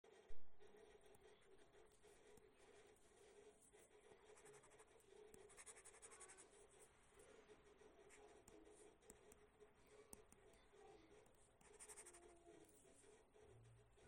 إن هذا الإحساس هو بسبب نوع خاص من نهاية القلم الإلكتروني وغلاف الشاشة ذو السطح الخشن وهناك شركات تقدم أغلفة لمنح نفس الإحساس على شاشات أجهزة أخرّى مثل PaperLike .
boox_Note_air_2_plus_writing_sound.mp3